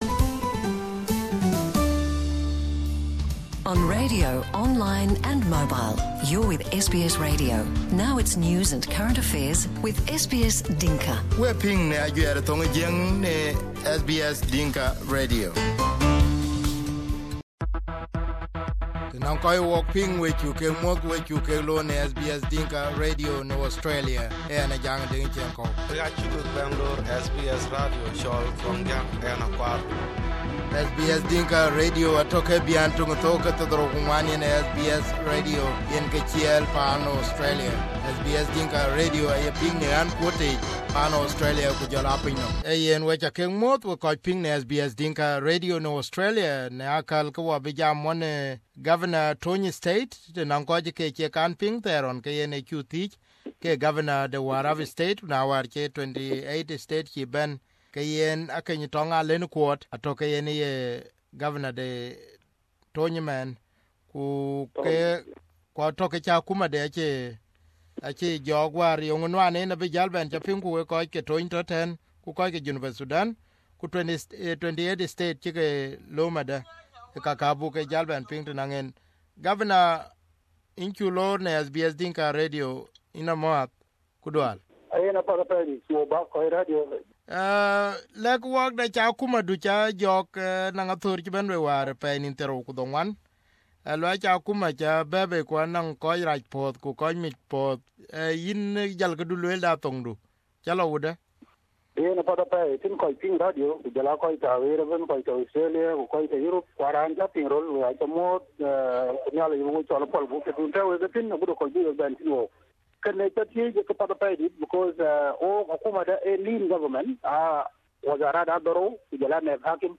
Akec Atong Aleu Kuot is now the new Governor of Tonj State and now speaks to us for the first time talk about his Government. Akec spoke about the fear that hunger is looming. Here is the interview.